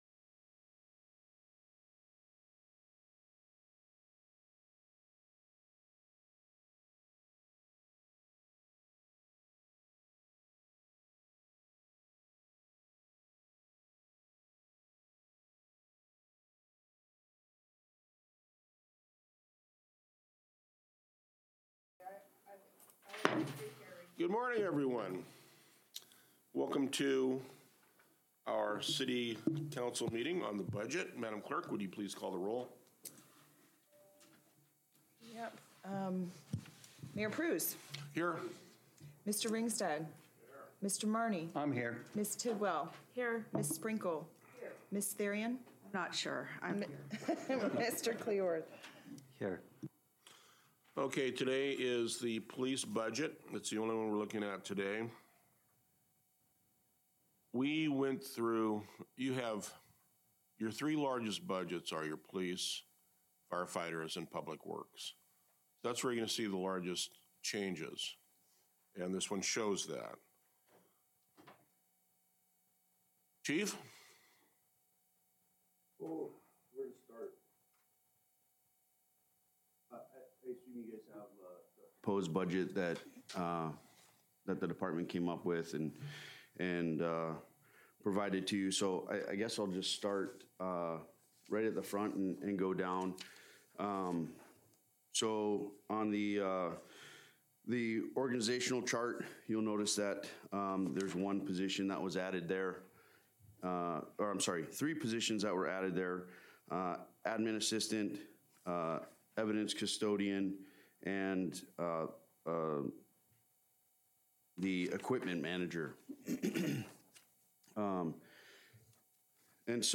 Council Budget Meeting